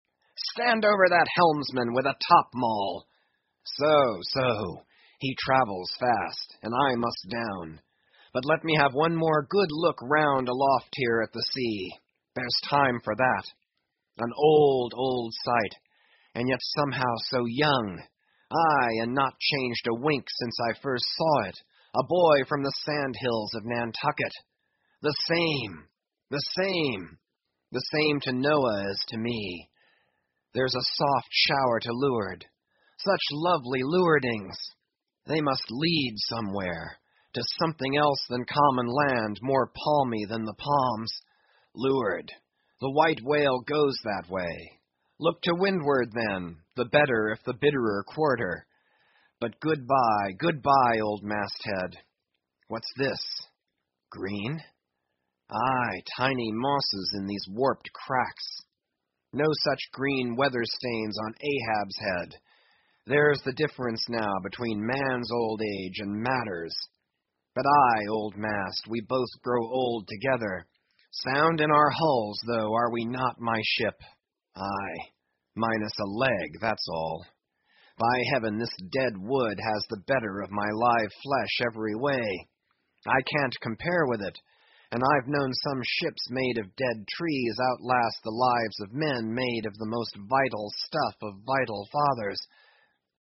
英语听书《白鲸记》第1035期 听力文件下载—在线英语听力室